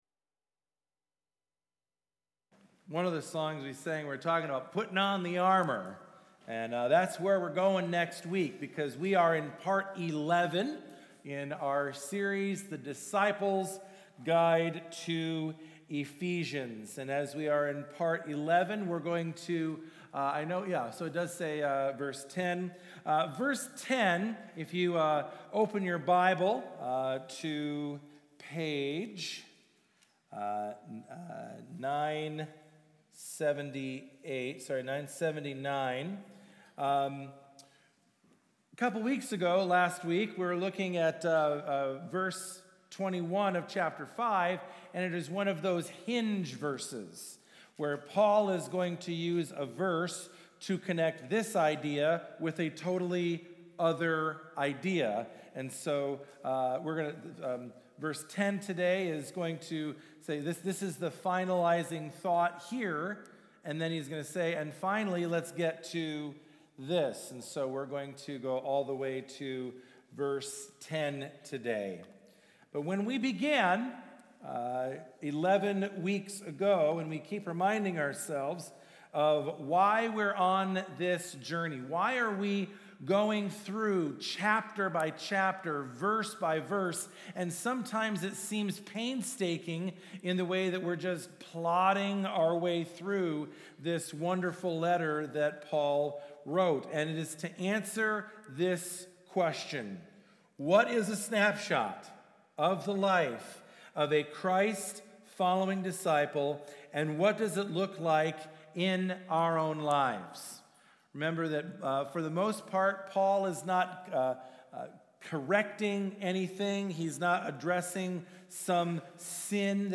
This Weeks Sermon